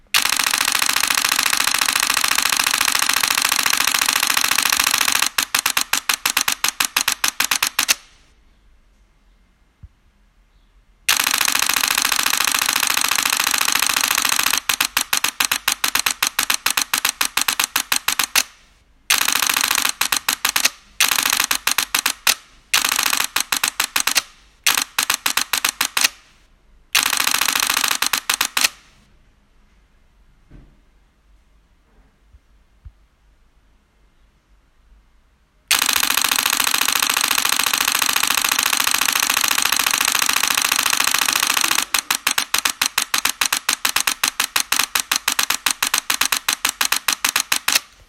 Sound Nikon D5 und D850 im Dauerfeuer
Nikon D5 – 14 Bilder sec. mit 128GB XQD Speicherkarte
D5-14-Bilder-Sec-Kopie.m4a